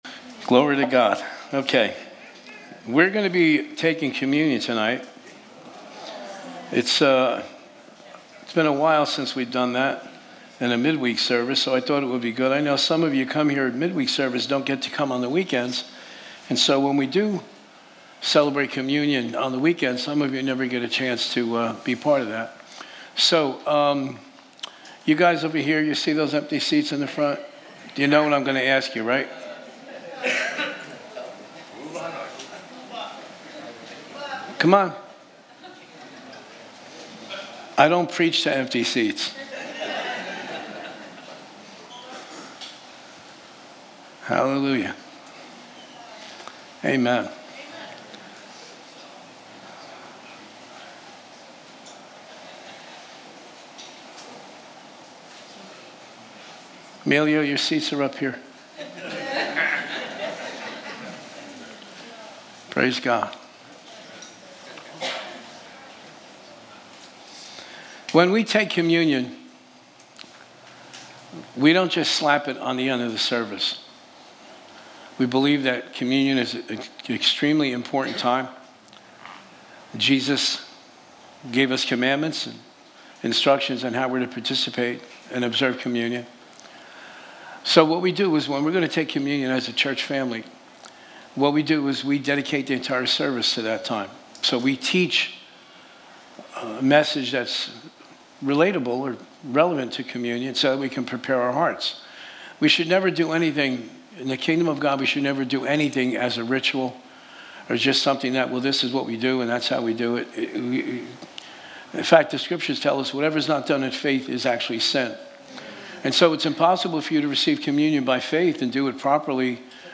Midweek message from New Beginnings Church!